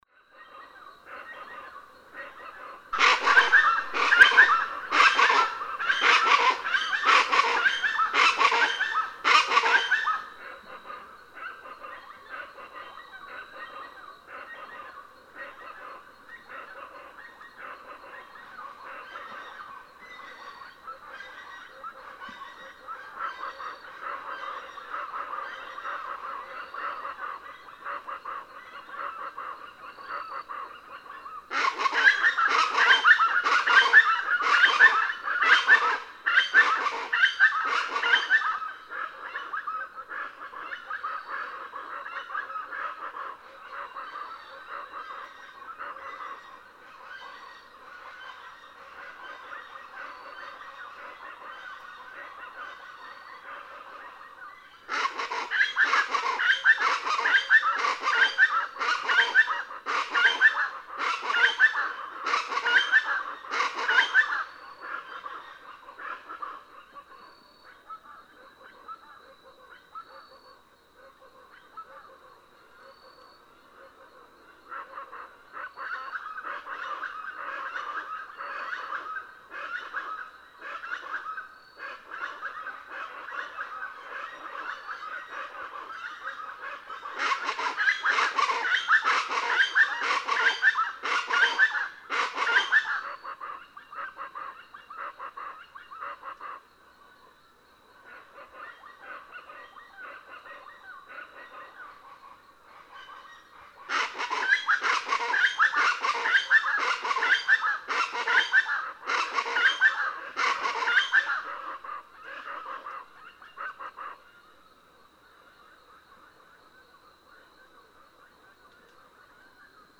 Plain chachalaca
In North America, it is a south Texas specialty, but this particular recording comes from Tikal National Park in Guatemala. This bird is named for its sounds, a loud, raucous, ear-shattering cha-cha-lac heard especially from awaking birds during early morning hours. A paired male and female duet, and the extended chorus from several pairs at dawn is exhilarating.
In this awaking chorus, the nearby male starts it off with his loud, low cha-cha-lac, the female immediately responding with her higher-pitched version. He's off to the left, she to the right in this stereo recording. Other pairs in the background sound off as well.
Tikal National Park, Guatemala.
710_Plain_Chachalaca.mp3